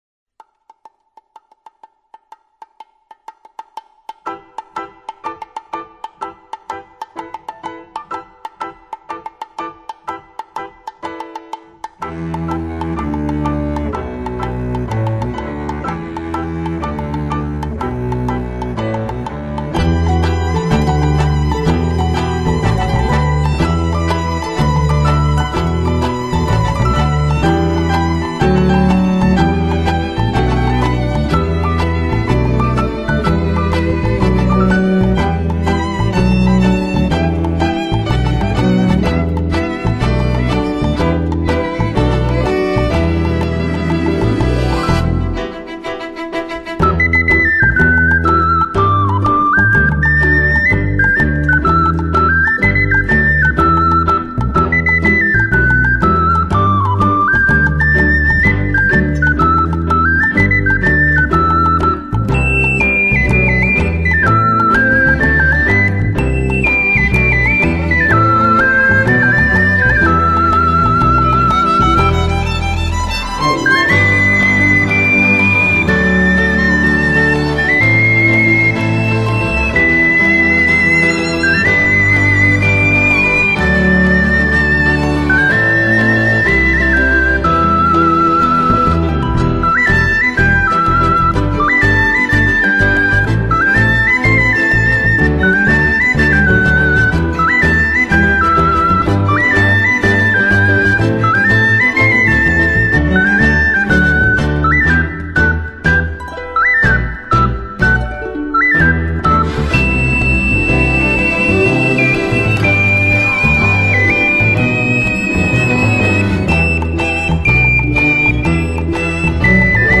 오카리나 연주곡 베스트 모음